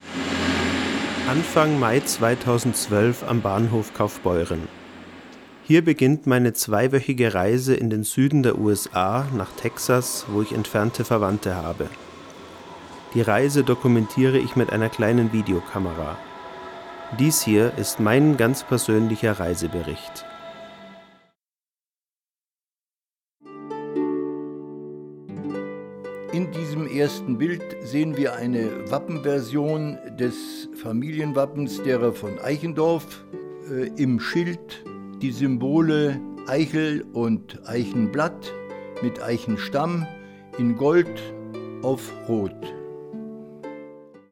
Hier kurze Ausschnitte beider Tonmischungen zum Vergleich:
Bei der 2. Aufnahme ist der Sprecher nur und ausschließlich auf der linken Tonspur. Der Sprecher ist zu leise gegenüber der Musik.
1. Sprecher top
2. Sprecher von links und etwas nuschlig